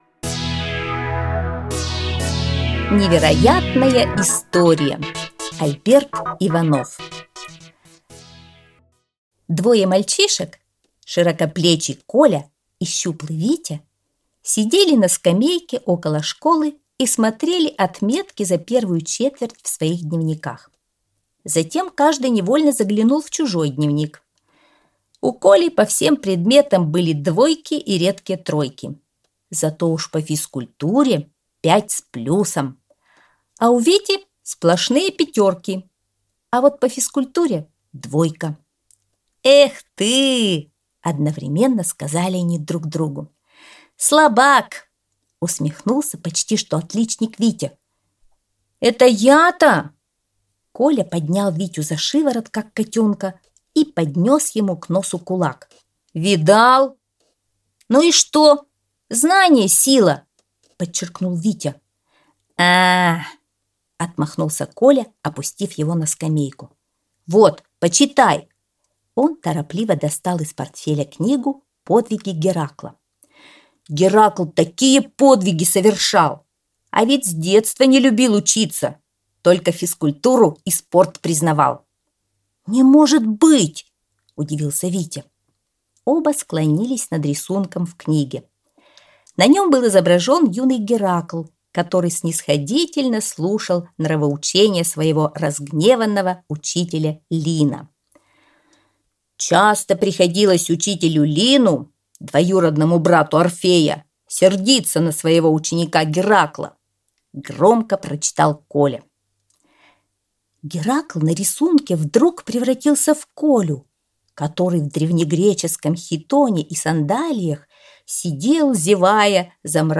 Невероятная история - аудиосказка Альберта Иванова - слушать онлайн